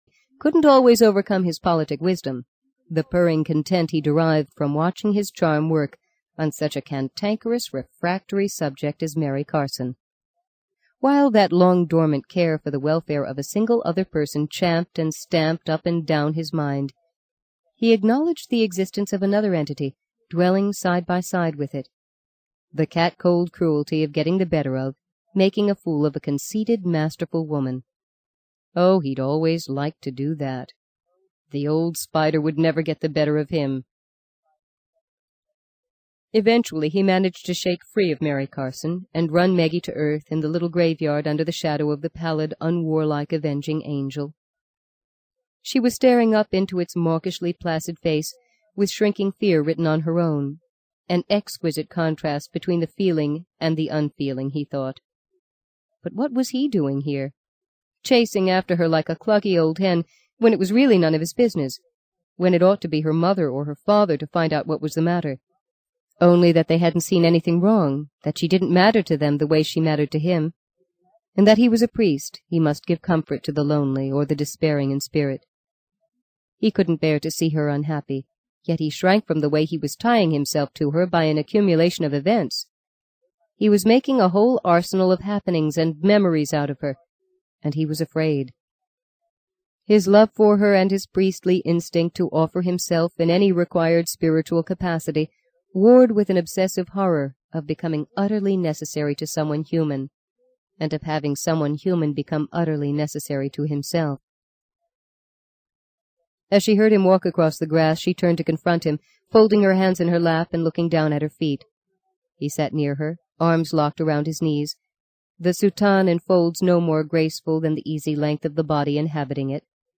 在线英语听力室【荆棘鸟】第六章 21的听力文件下载,荆棘鸟—双语有声读物—听力教程—英语听力—在线英语听力室